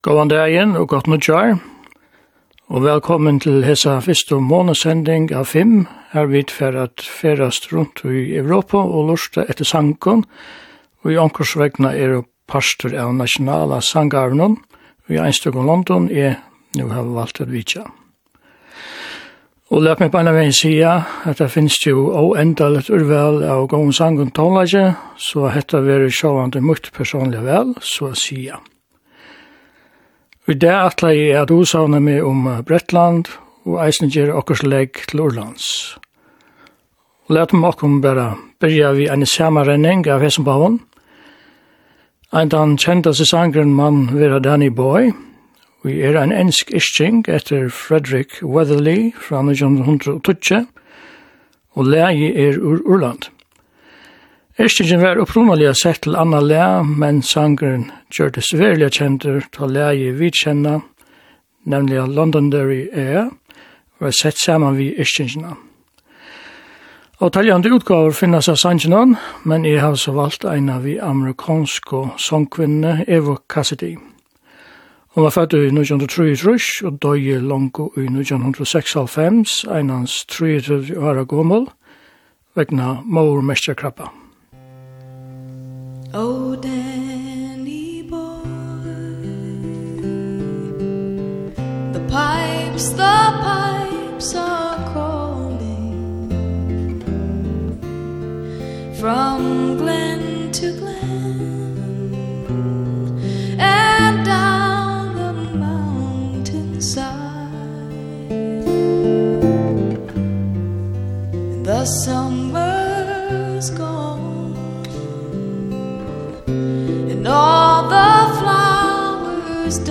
Styttri tónleikasendirøðir og einstakar tónleikasendingar við skiftandi vertum.